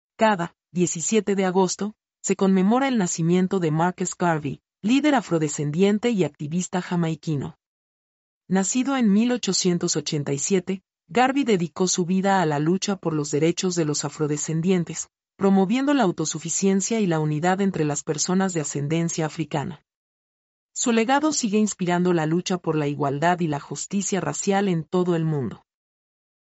mp3-output-ttsfreedotcom-82-1.mp3